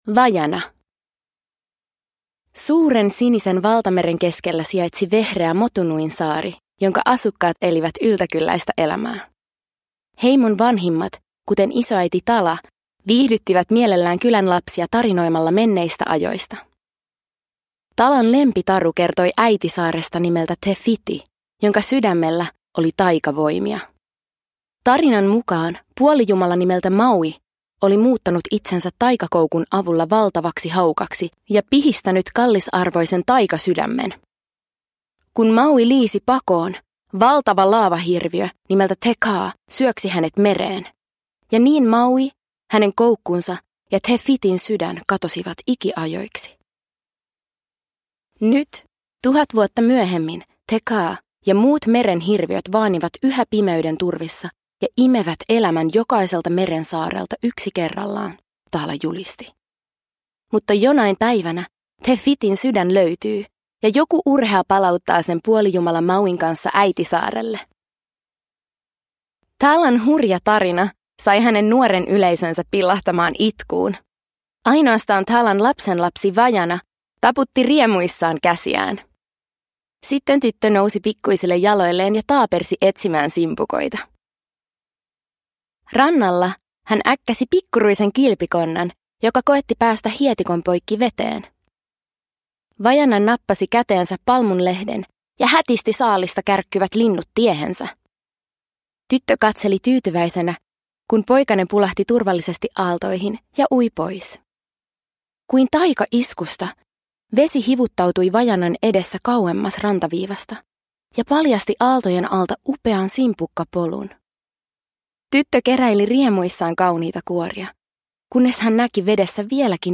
Vaiana. Satuklassikot – Ljudbok – Laddas ner